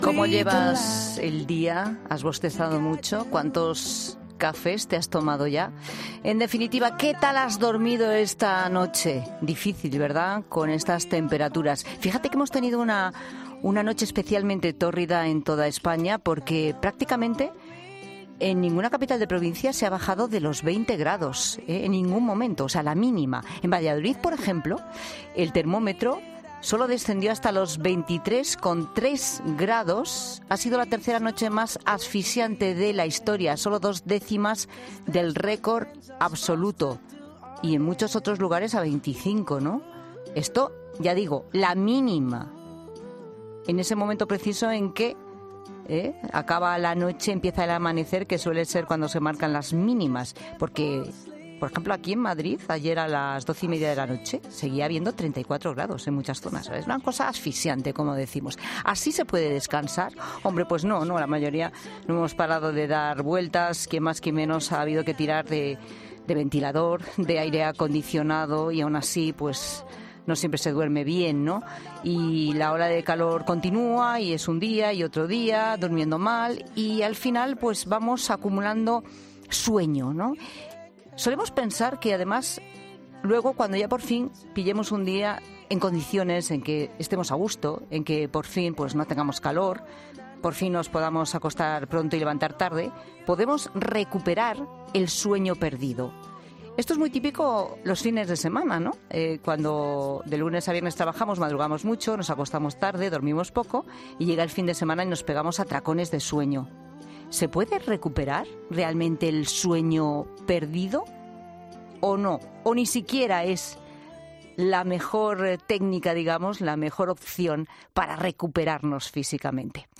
Un doctor ha explicado en 'La Tarde' los síntomas que podemos padecer si no descansamos lo suficiente y ha detallado también cómo debemos regular nuestro sueño para dormir bien